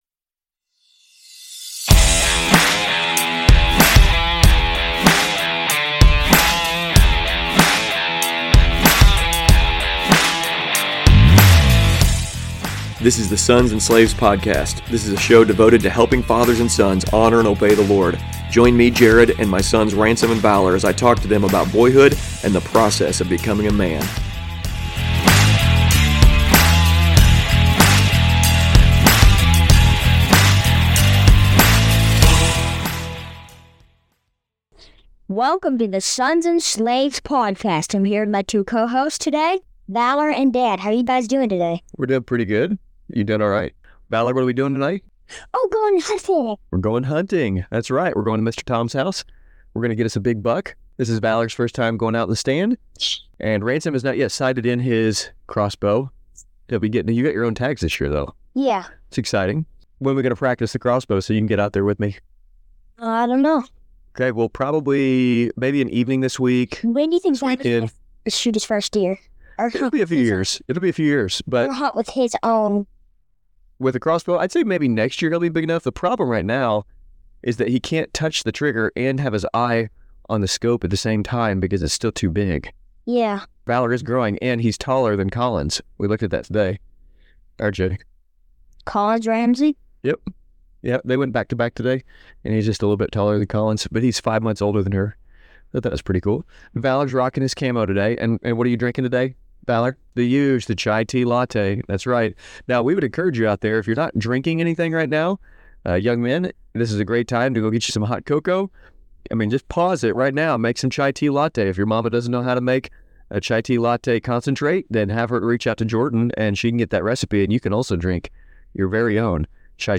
We had some editing issues with this episode.